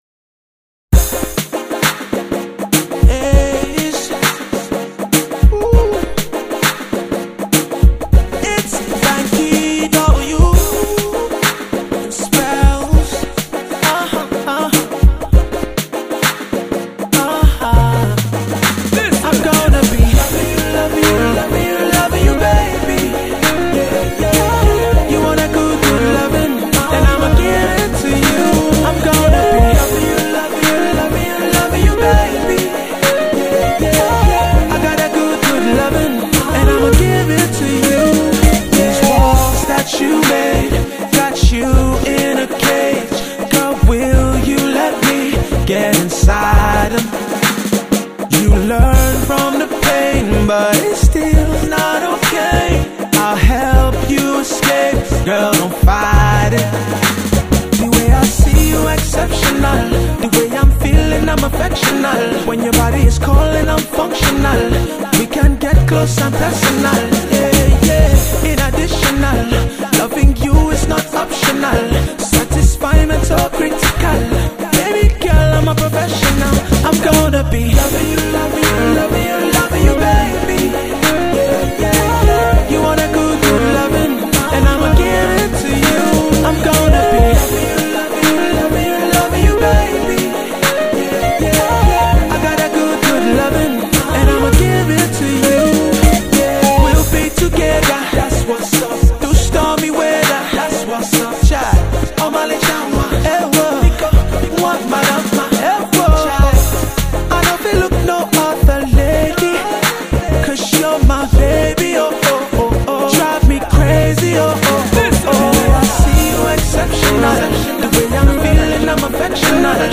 R&B act